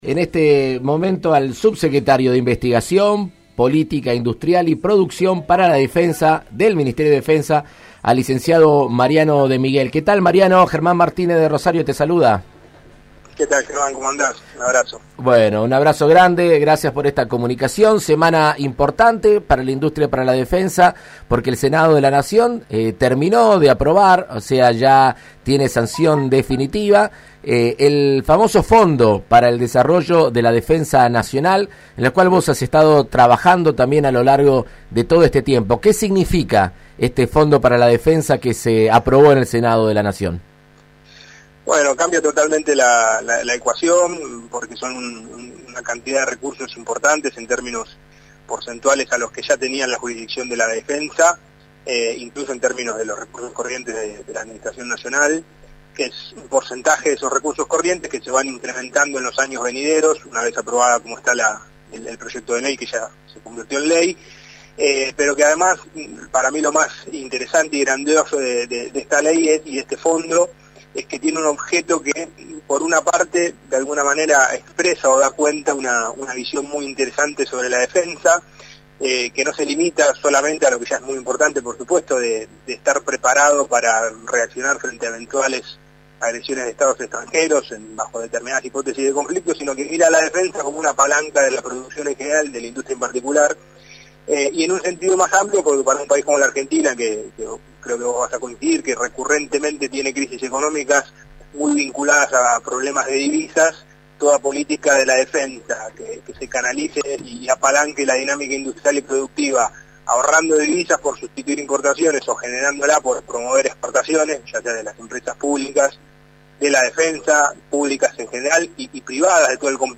El Senado aprobó esta semana el proyecto de Fondo Nacional de la Defensa (Fondef). En Argentina Unida contra el Coronavirus, el diputado nacional Germán Martínez dialogó con el Subsecretario de Investigación Científica y Política Industrial para la Defensa.